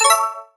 gem_collect.wav